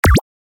دانلود صدای موس 12 از ساعد نیوز با لینک مستقیم و کیفیت بالا
جلوه های صوتی